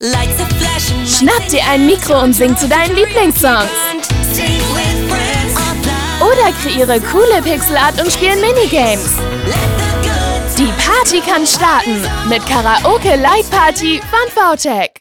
hell, fein, zart, plakativ
Jung (18-30)
Commercial (Werbung)